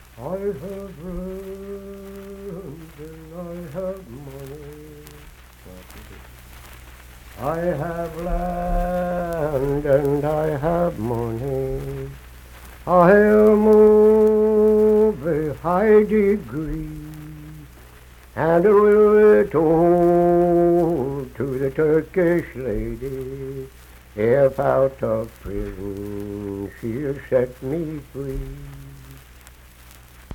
Unaccompanied vocal music performance
Birch River, Nicholas County, WV
Verse-refrain 1(4).
Voice (sung)